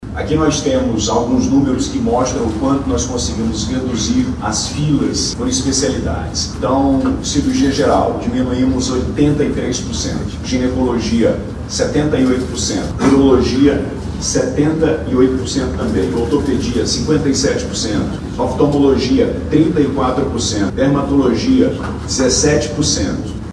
SONORA-2-WILSON-LIMA.mp3